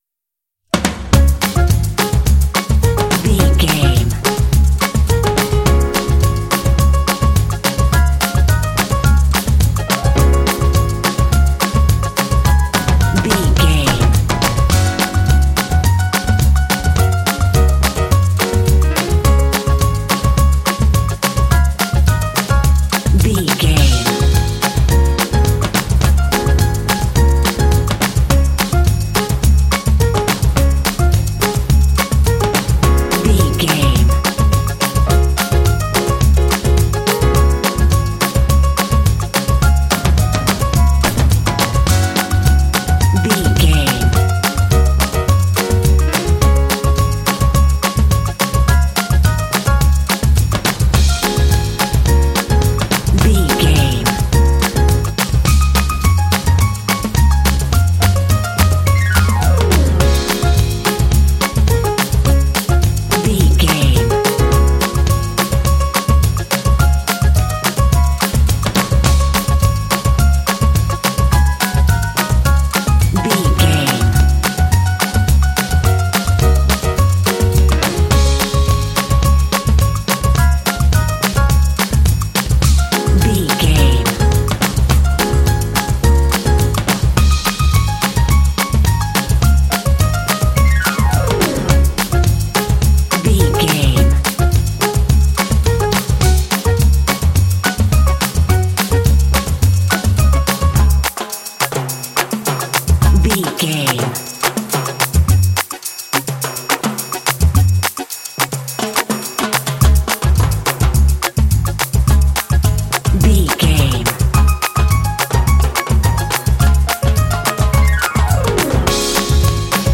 Uplifting
Ionian/Major
D♭
fun
happy
bass guitar
drums
percussion
piano
latin jazz